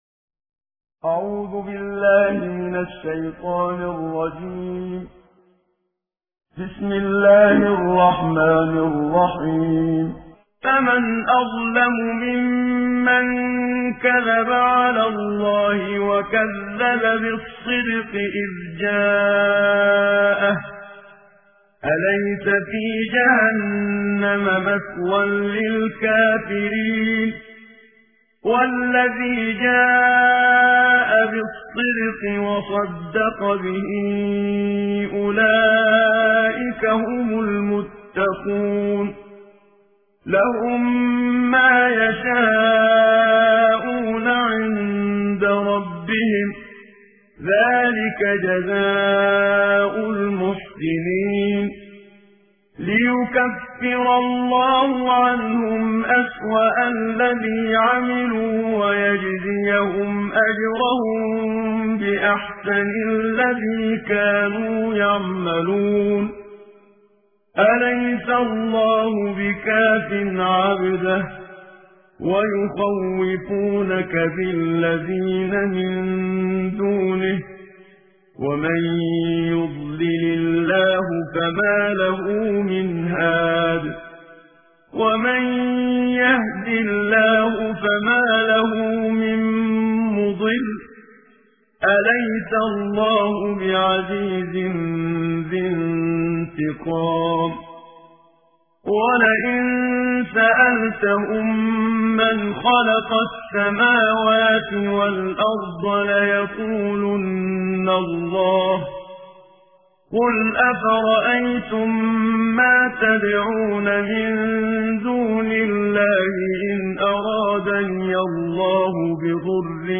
صوت تلاوت ترتیل جزء بیست‌وچهارم قرآن کریم